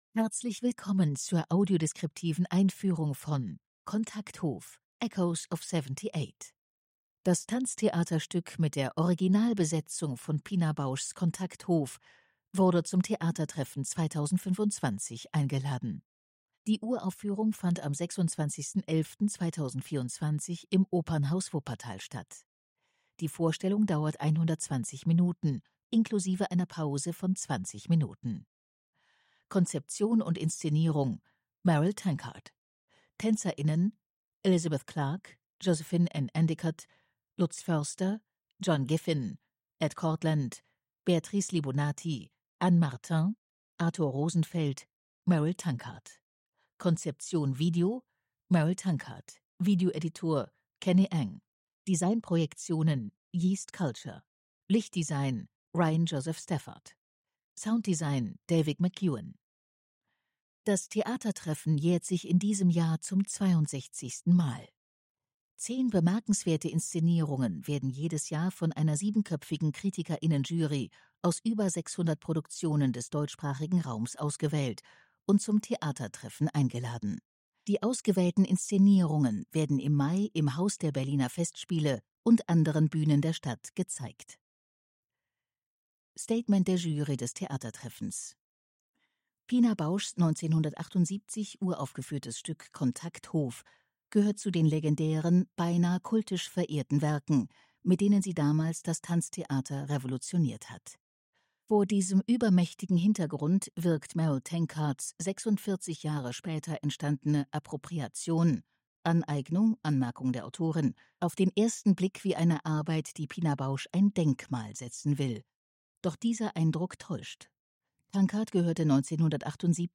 tt25_audiodeskription_kontakthof.mp3